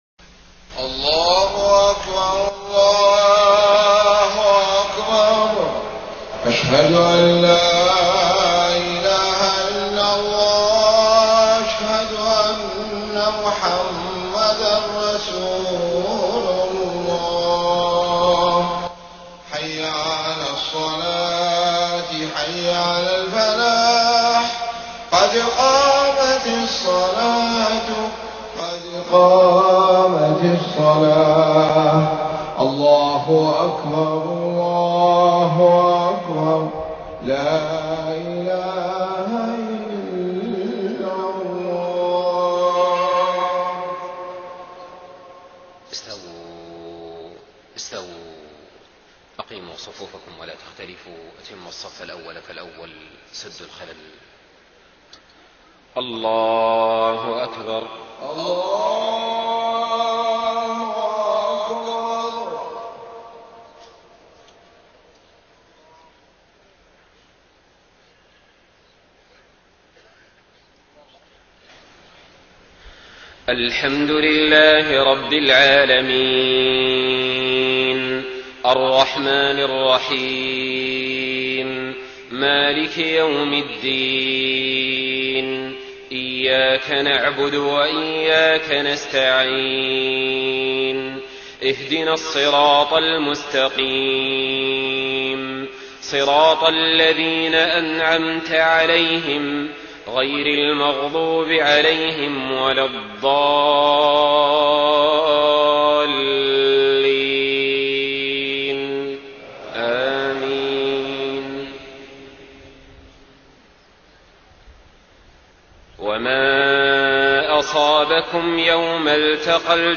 صلاة العشاء 1 محرم 1430هـ من سورة آل عمران 166-179 > 1430 🕋 > الفروض - تلاوات الحرمين